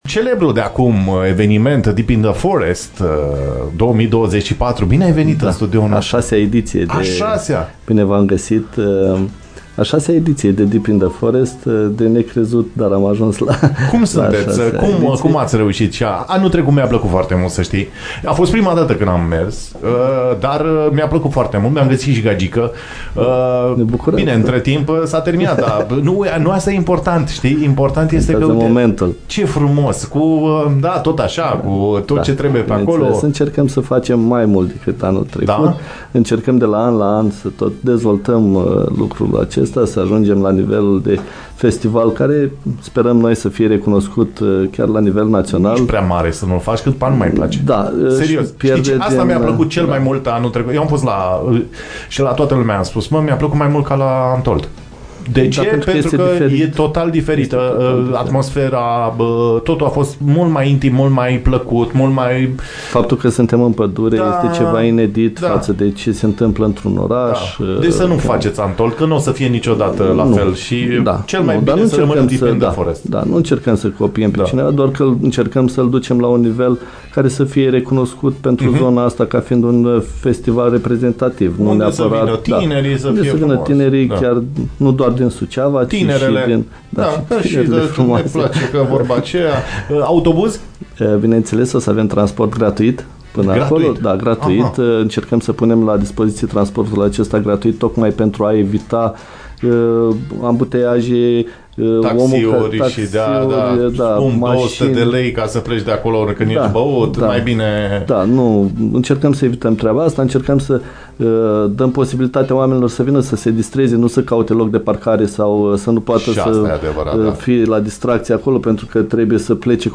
Avanpremiera celei de-a 6 -a ediții a fost asigurată, live